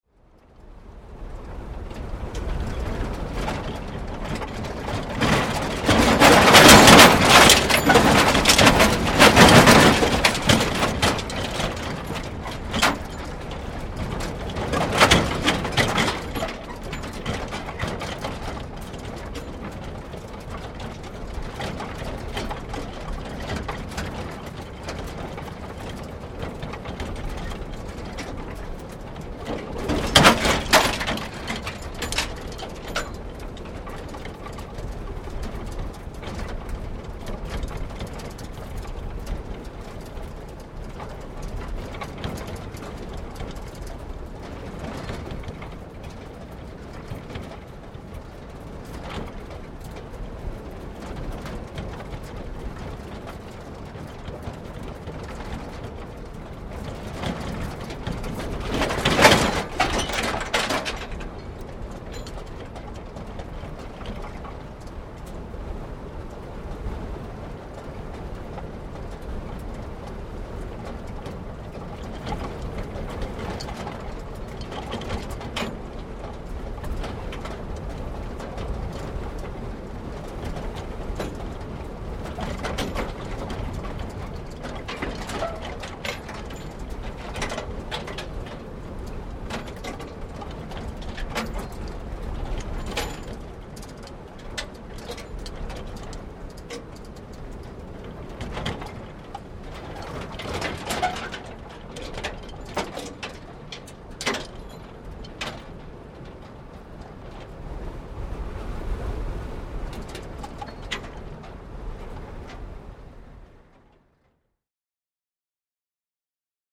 Звук эвакуатора с автомобилем после аварии, едущего по ямам